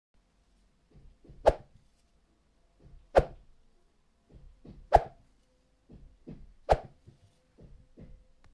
swish (x4)